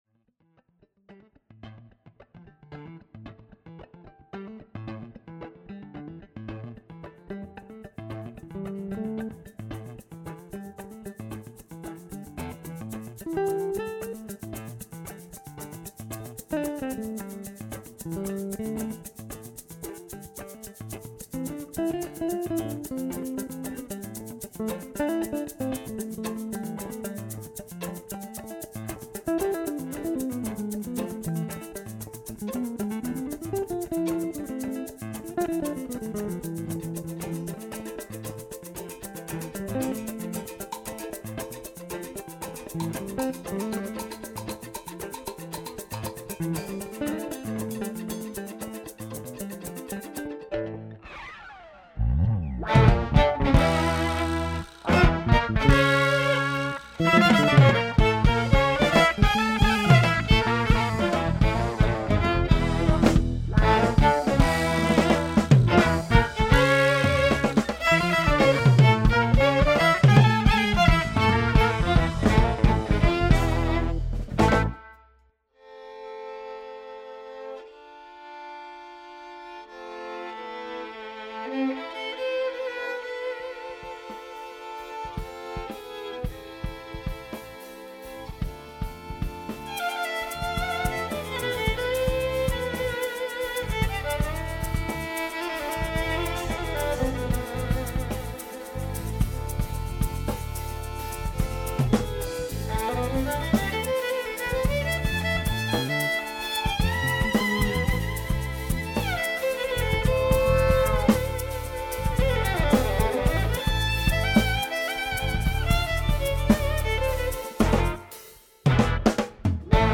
DC featuring jazz, funk, rock and folk.